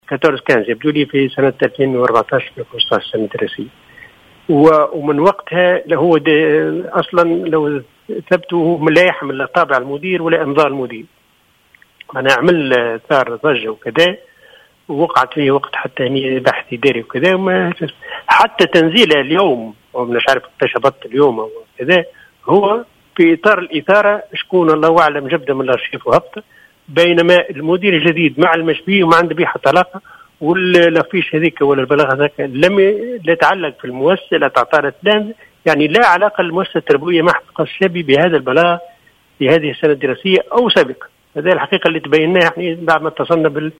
وأوضح المندوب الجهوي في تصريح للجوهرة أف أم، أن هذا البلاغ المفبرك الذي لا يحمل أي ختم رسمي أو إمضاء من إدارة المعهد، قد تم تداوله سابقا في السنة الدراسية 2014/2015، بهدف إثارة الرأي العام حيث تم فتح تحقيق في الموضوع حينها، وأثبت عدم صحته، ليقوم مجهولون مؤخرا بإعادة نشره.